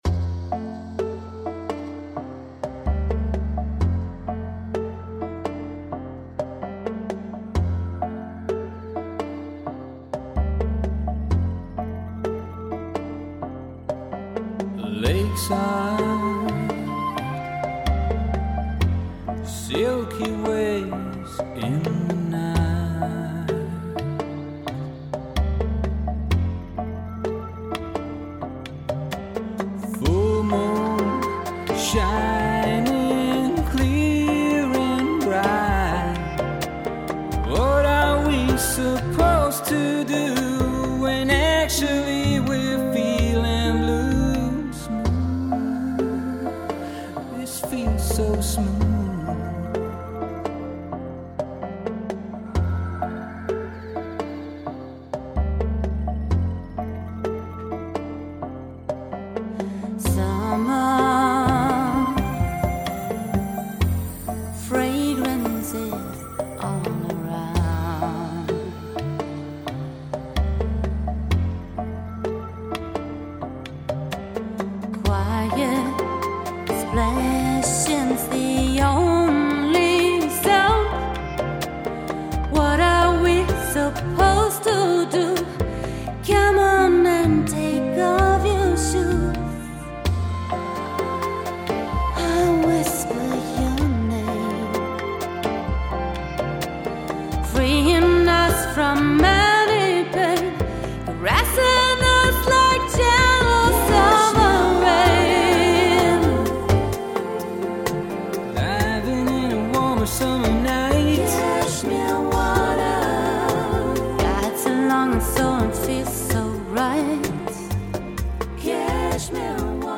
IM STUDIO 2009
Gesang